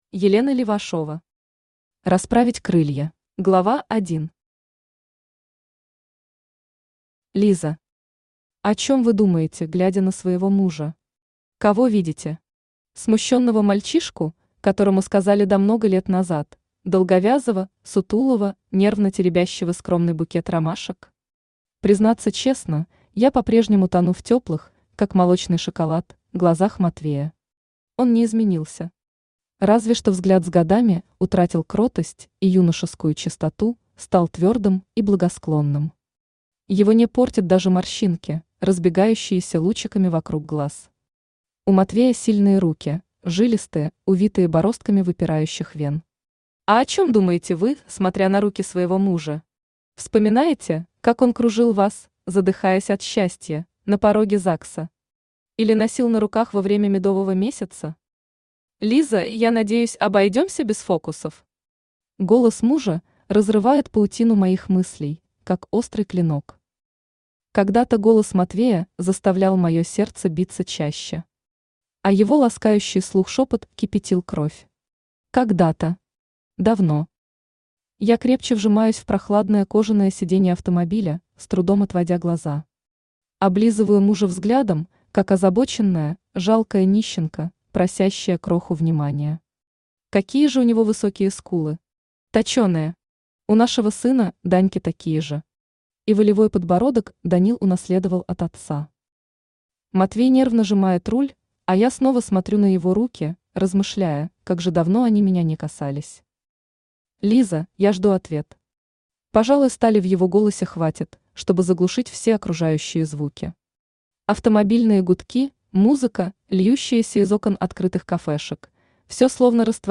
Аудиокнига Расправить крылья | Библиотека аудиокниг
Aудиокнига Расправить крылья Автор Елена Левашова Читает аудиокнигу Авточтец ЛитРес.